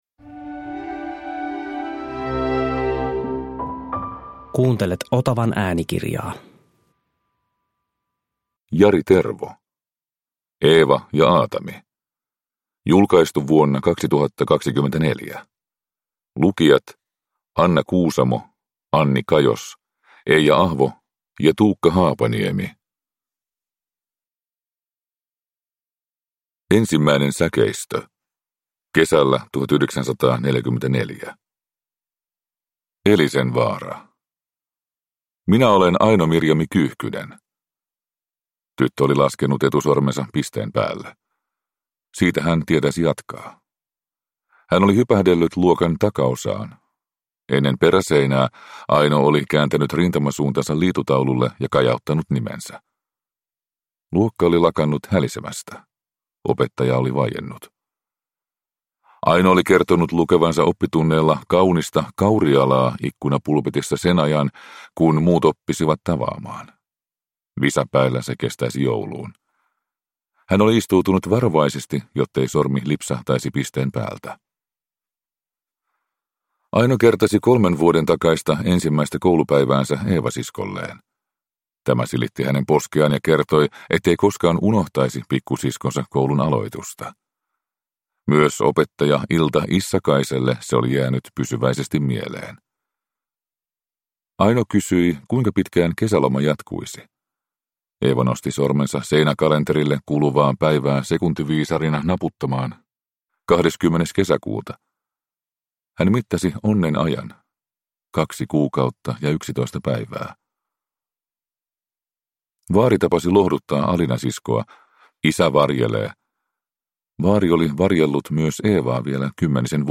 Eeva ja Aatami – Ljudbok